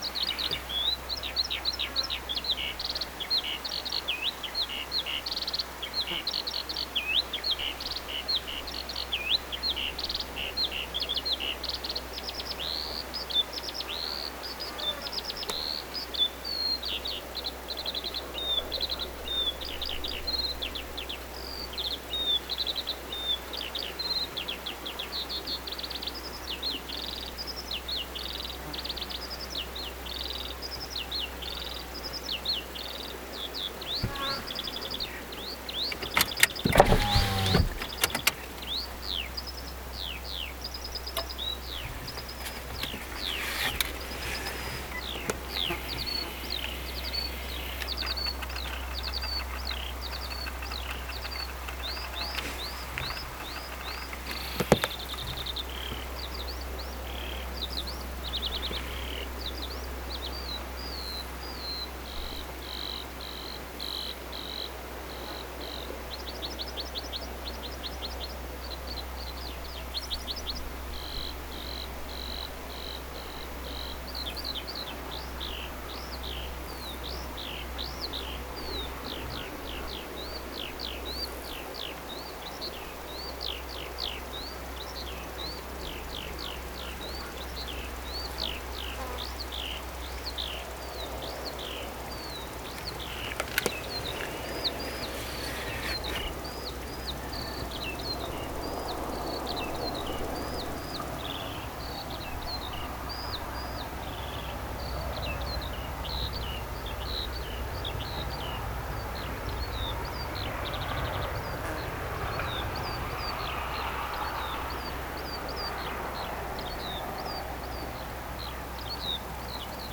vähän erikoisesti laulava kiuru,
surinatyyppisiä ääntelyjä paljon
Sekin on erikoista, että se ylipäätään laulaa.
vahan_erikoisesti_laulava_kiuru_paljon_kuin_surinatyyppisia_aantelyja_normaalia_paljon_enemman.mp3